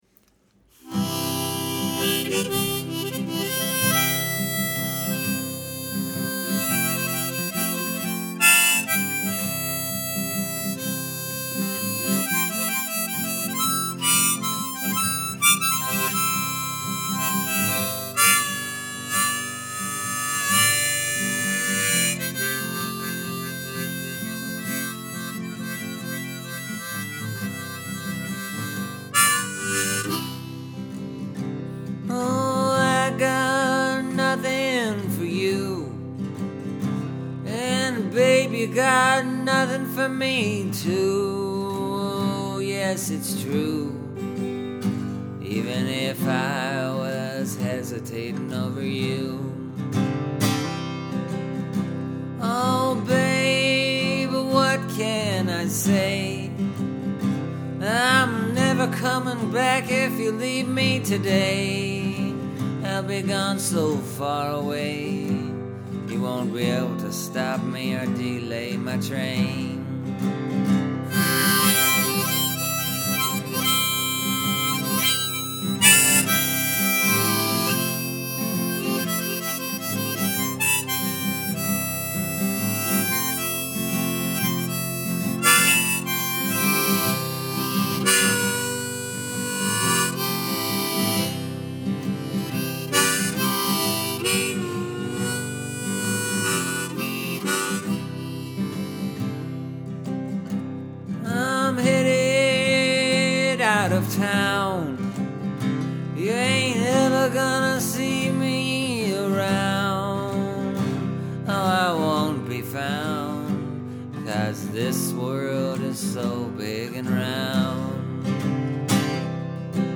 Proving the point that any song can be made into a three-quarter time ballad.